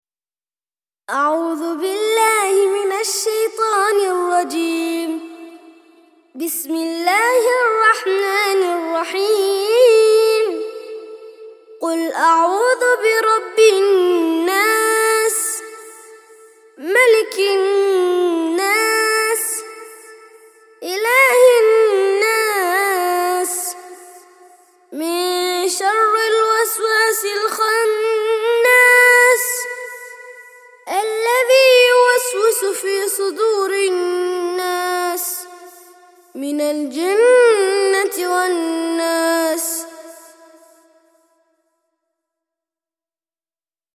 114- سورة الناس - ترتيل سورة الناس للأطفال لحفظ الملف في مجلد خاص اضغط بالزر الأيمن هنا ثم اختر (حفظ الهدف باسم - Save Target As) واختر المكان المناسب